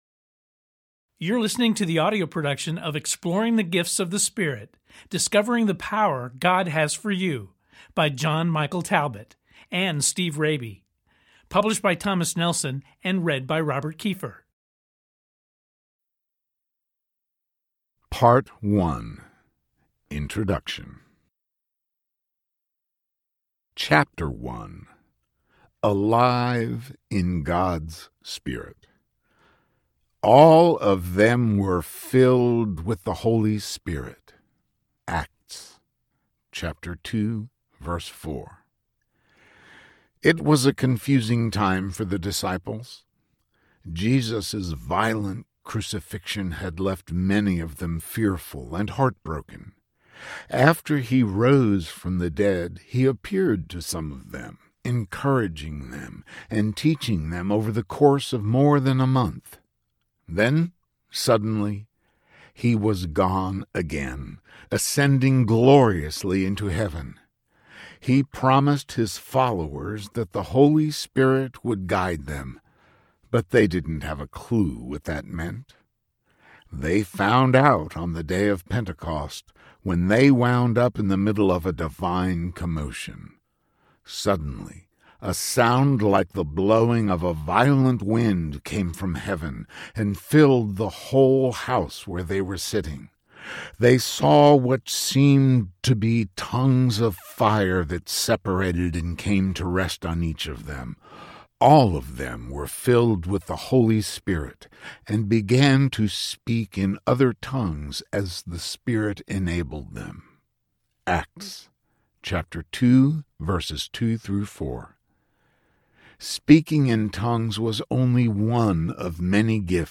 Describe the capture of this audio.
6.5 Hrs. – Unabridged